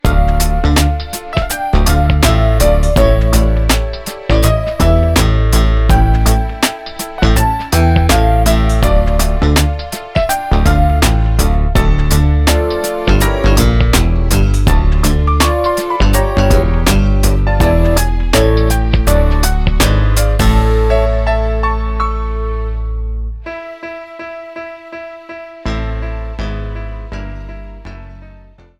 Classical music with a modern edge